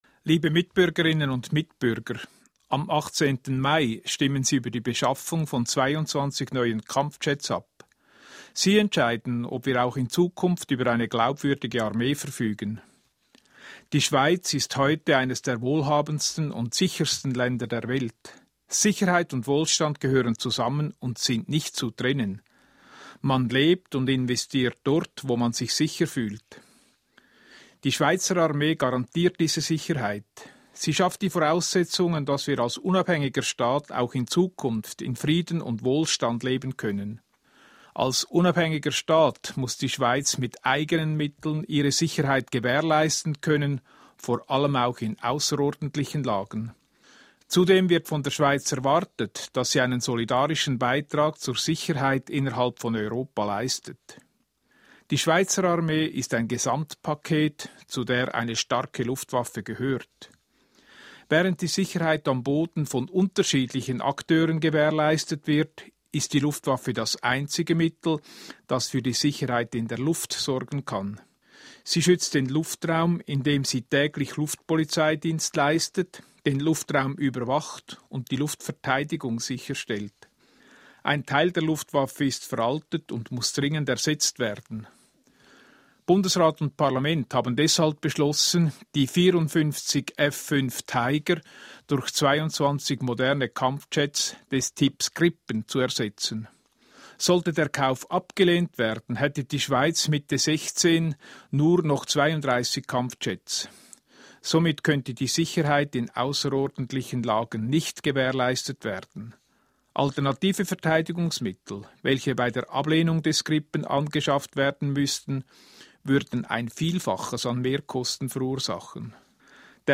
Bundesrat Ueli Maurer